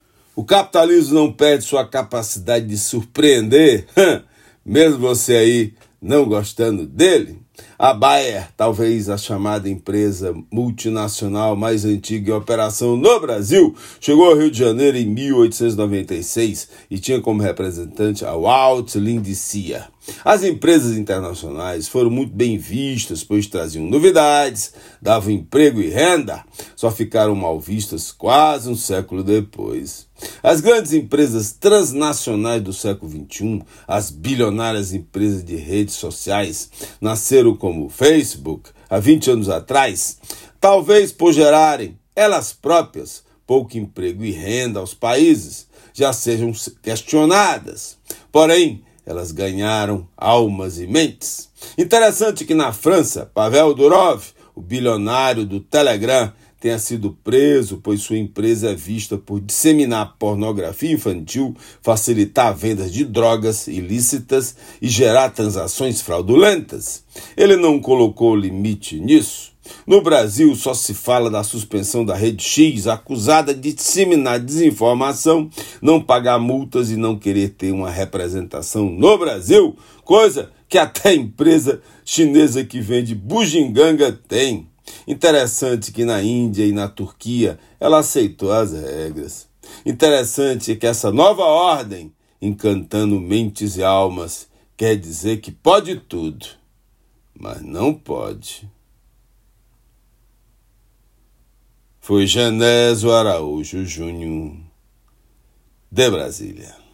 Comentário desta segunda-feira (02/09/24)
direto de Brasília.